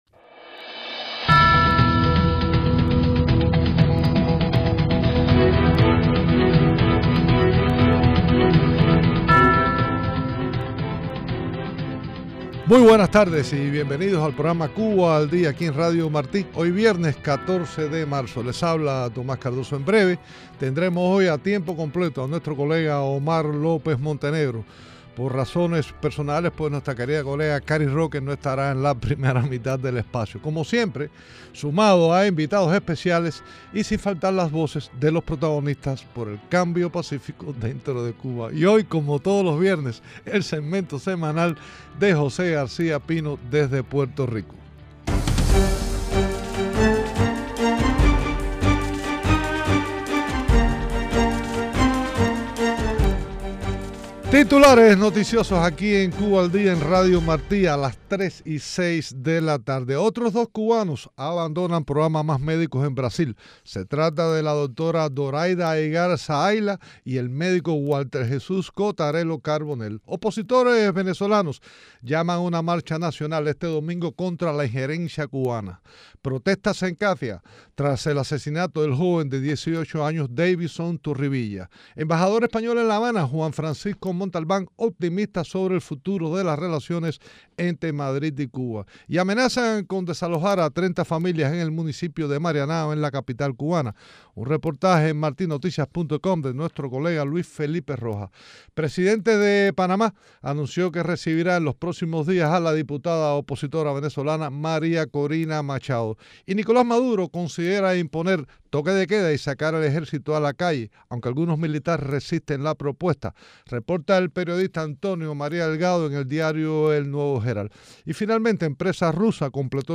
El alcalde explica la situación de esta ciudad venezolana.